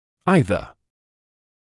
[‘aɪðə][‘айзэ]один из двух; тот или другой; также, тоже (в отрицательных предложениях)